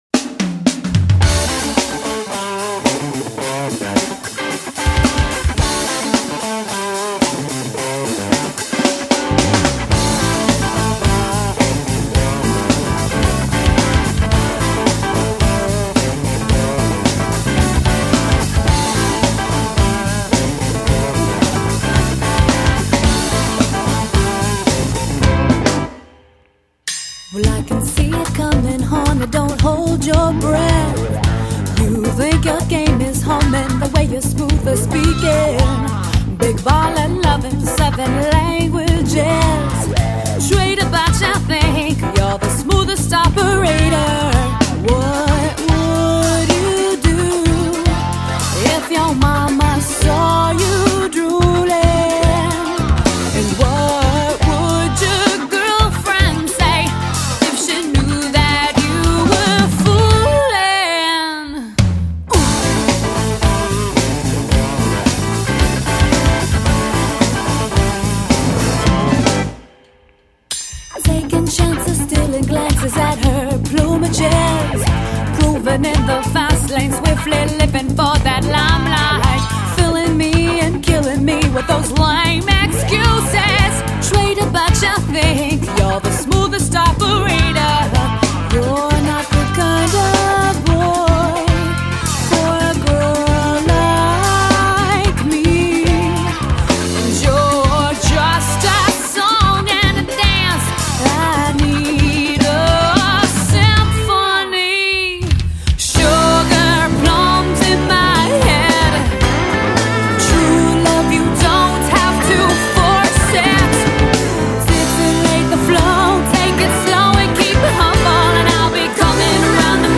Genre: Pop Rock